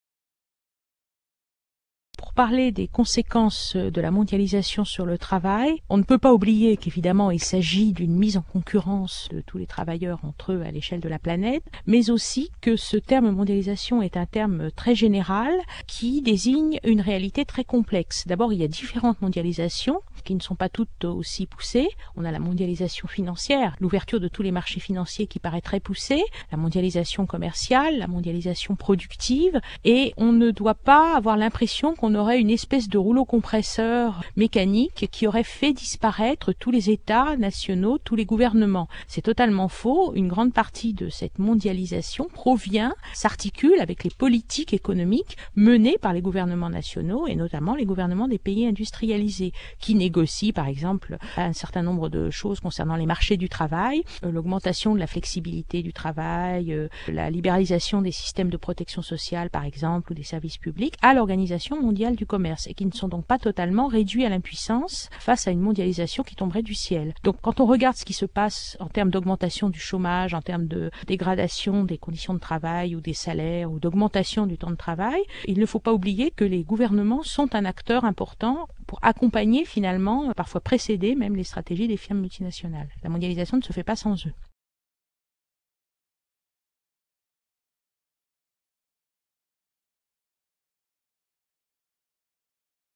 Cet interview a été réalisé à l’issue de la projection du documentaire de Marie France Collard, Ouvrières du monde, (Belgique, 2000. 52’).